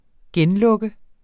Udtale [ ˈgεnlɔgə ]